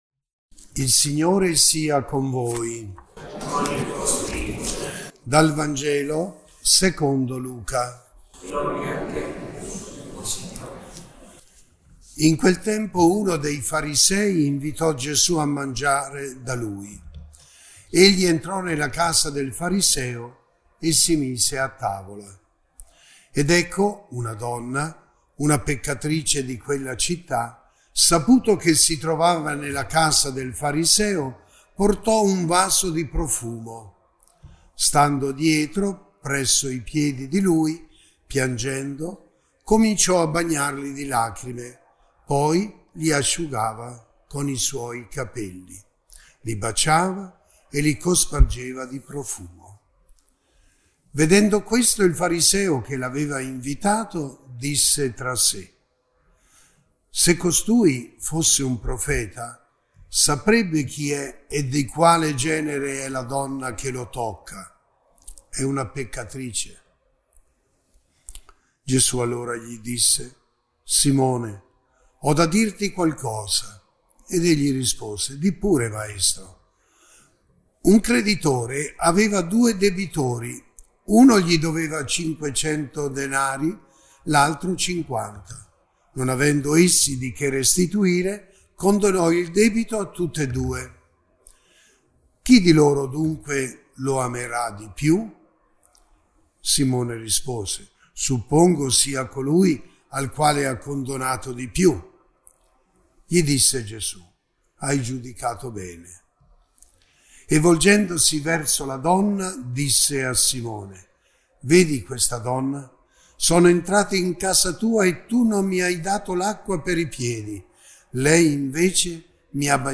Il Vescovo ha concluso il Quaresimale leggendo “La peccatrice” un testo di Marina Marcolini all’interno del libro “Per voce di donna”.
audio-terzo-quaresimale.mp3